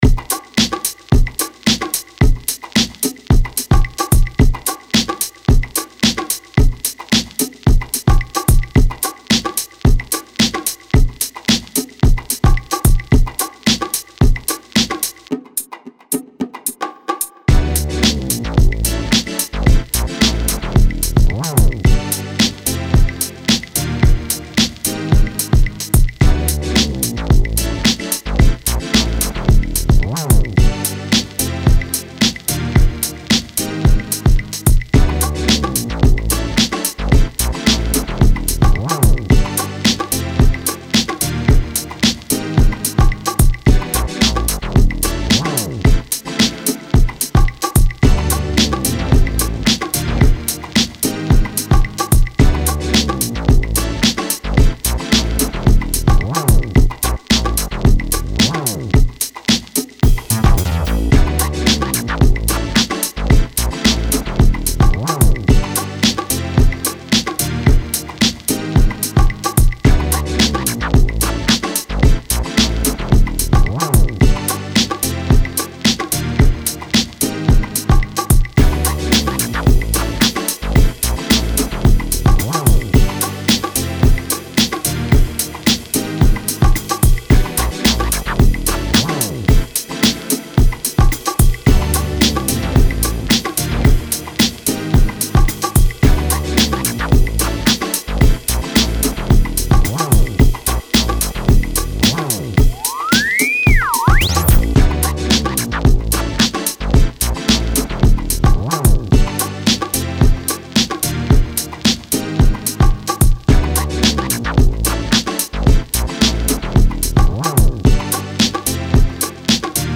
Genre Funk